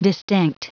Prononciation du mot distinct en anglais (fichier audio)
Prononciation du mot : distinct